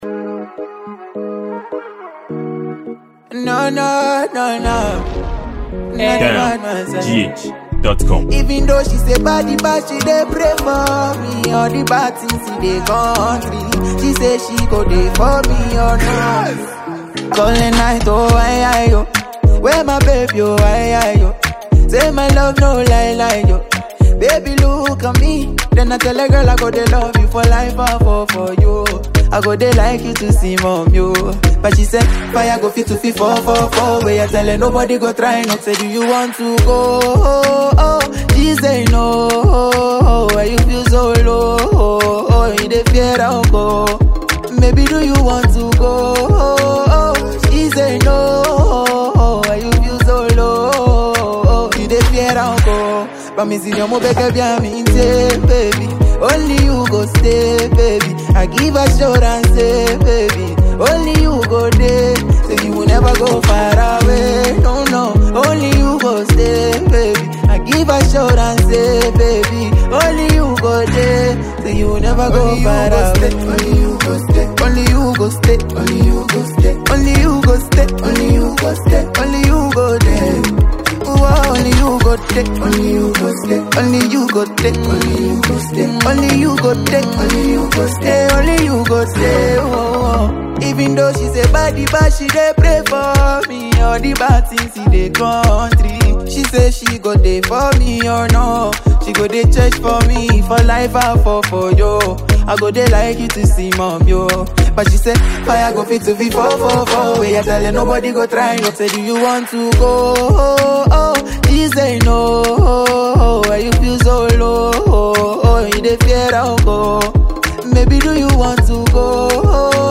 Talented singer and afrobeat Ghanaian musician
afrobeat song
Catchy lyrics